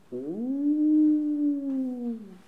bruit-animal_15.wav